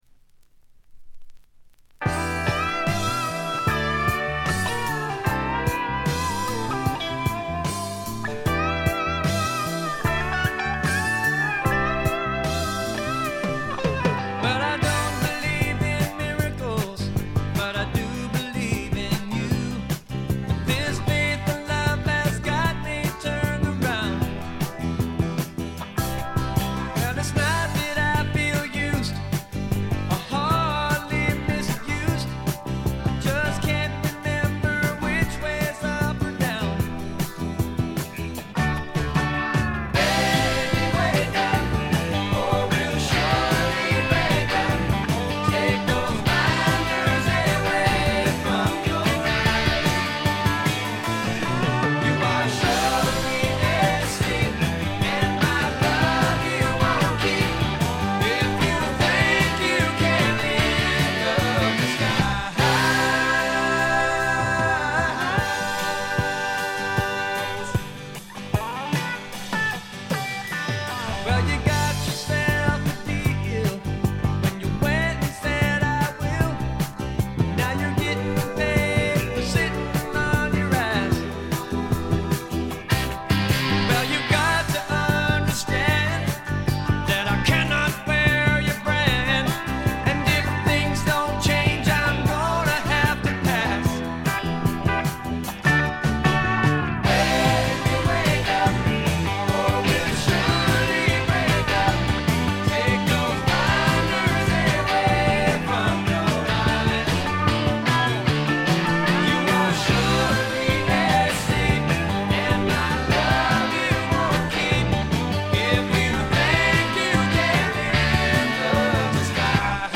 ほとんどノイズ感無し。
美しいコーラスが特に気持ち良いです。
試聴曲は現品からの取り込み音源です。
Recorded At - Kaye-Smith Studios